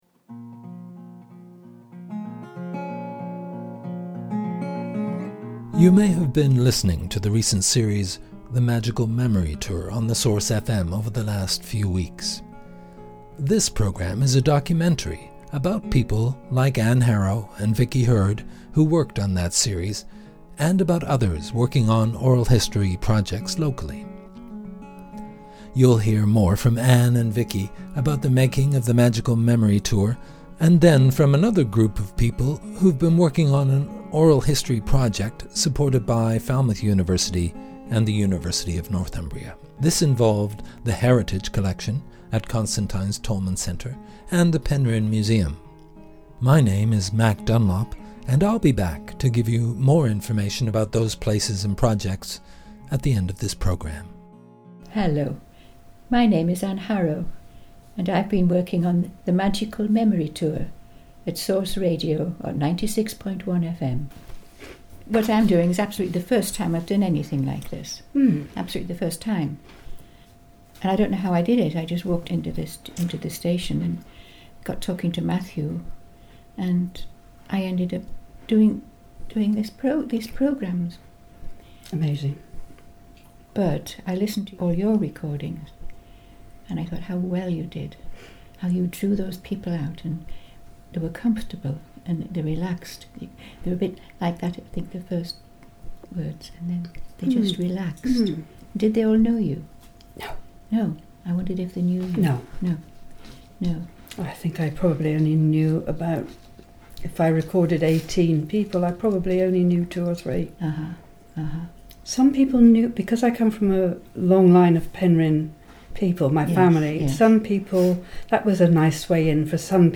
In this project community groups share their stories by making radio programmes.
oral-history.mp3